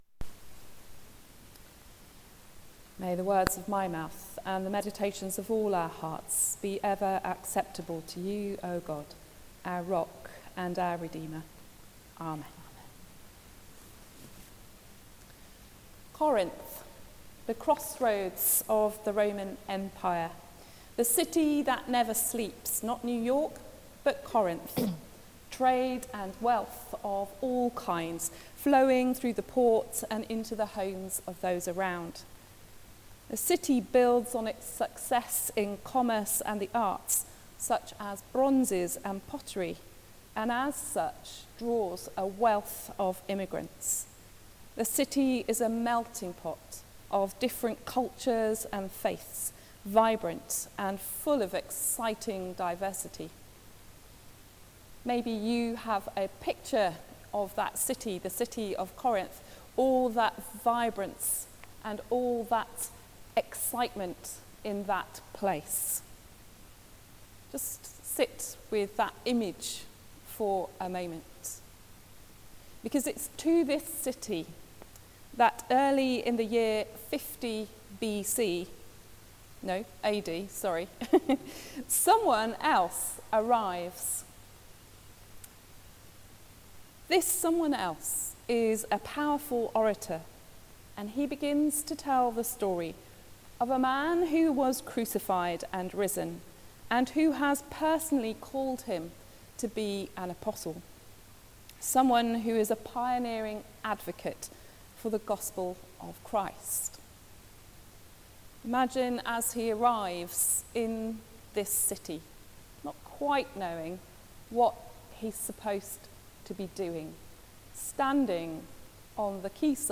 Sermon: Stay Awake. Stay Alert.